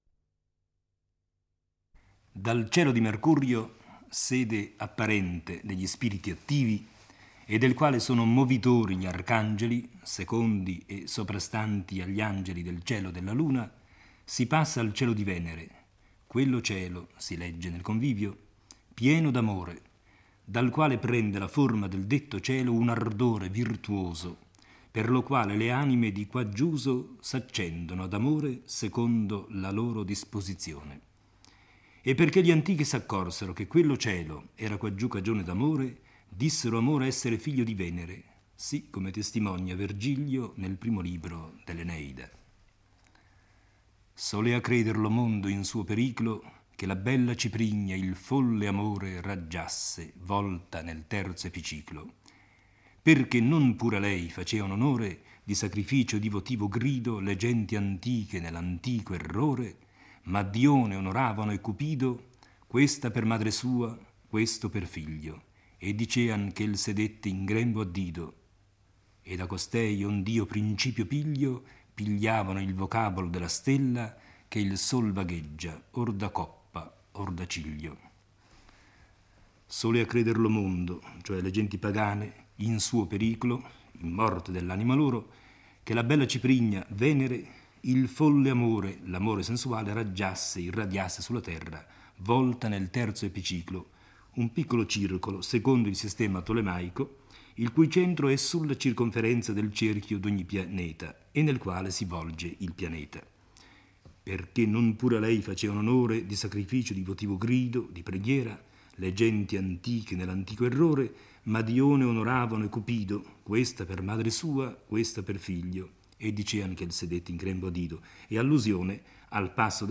legge e commenta il VIII canto del Paradiso.